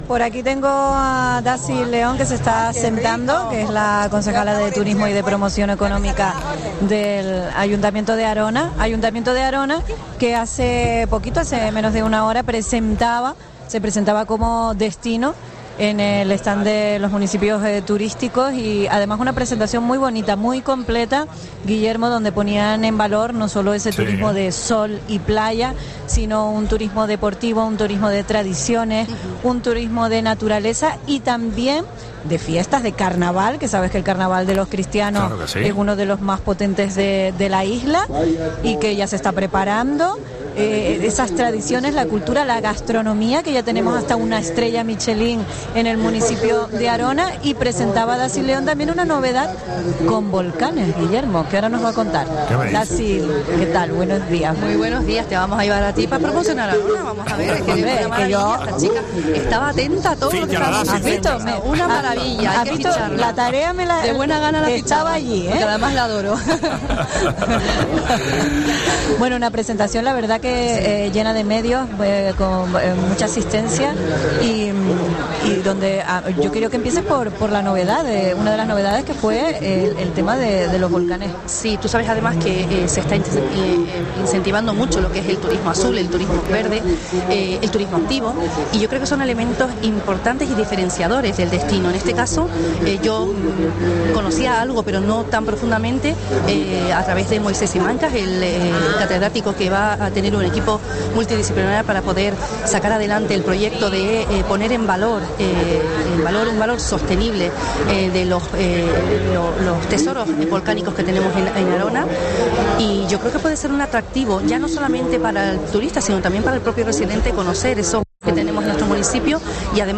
Entrevista a Dácil León, concejala de Turismo y Promoción Económica de Arona, en FITUR 2024